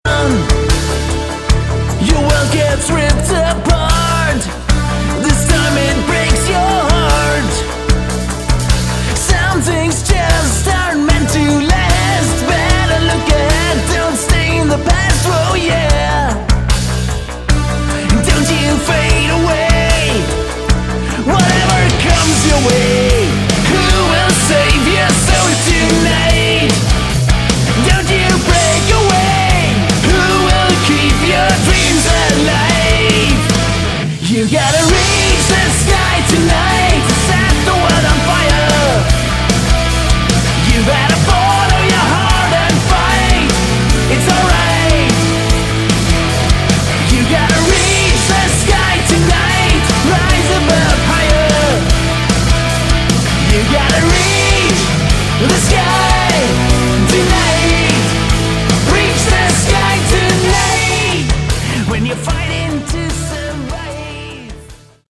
Category: Hard Rock
vocals, guitar, bass and keyboards
drums